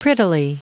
Prononciation du mot prettily en anglais (fichier audio)
prettily.wav